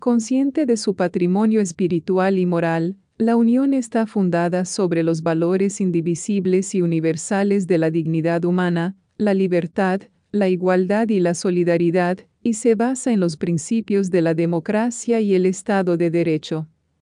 Our Voice Over Portfolio